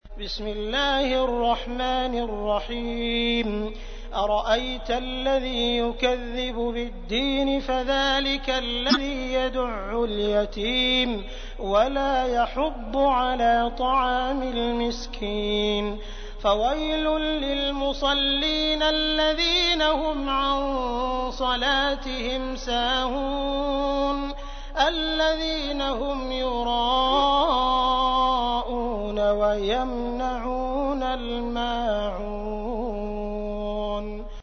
تحميل : 107. سورة الماعون / القارئ عبد الرحمن السديس / القرآن الكريم / موقع يا حسين